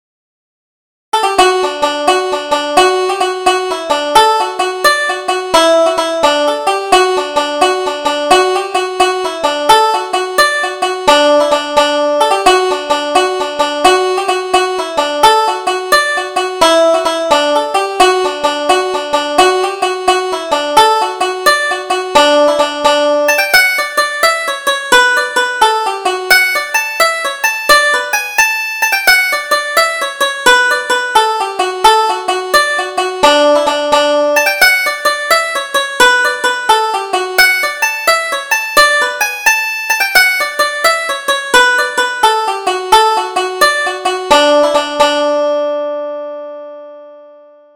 Double Jig: Paddy O'Carroll